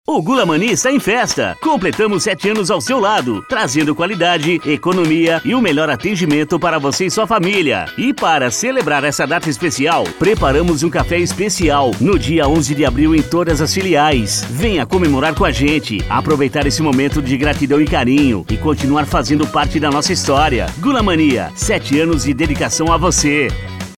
SPOT GULA MANIA:
Impacto
Animada